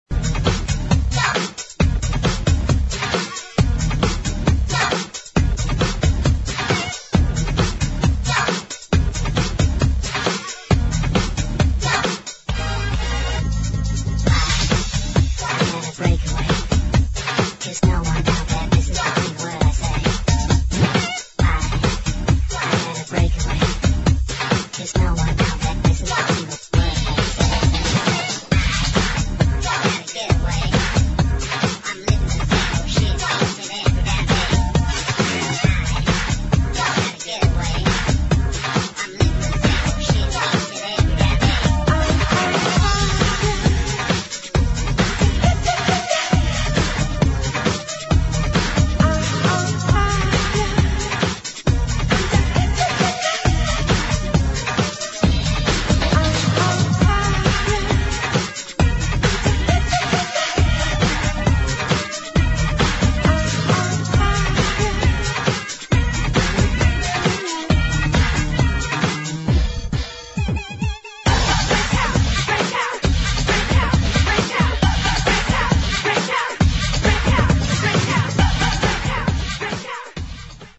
BREAKS